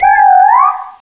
pokeemerald / sound / direct_sound_samples / cries / yamask.aif